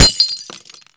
glass1.wav